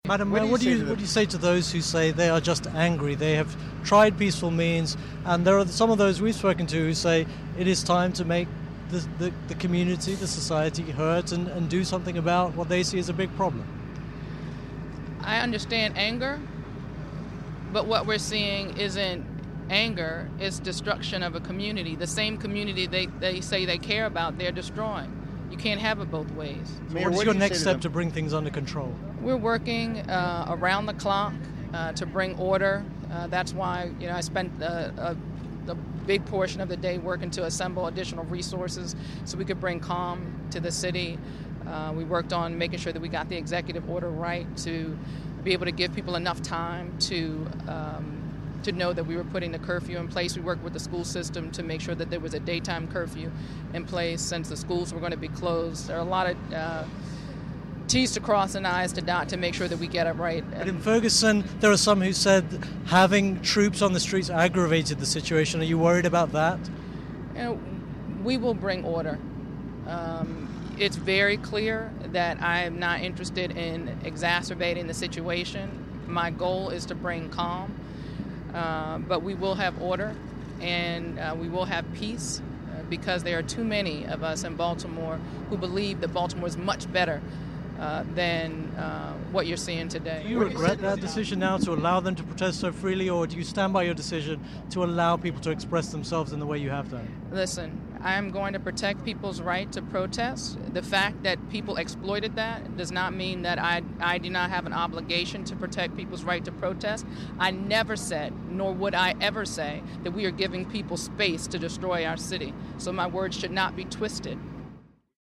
Interview with Baltimore's Mayor about the day's events